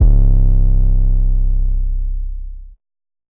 REDD 808 (11).wav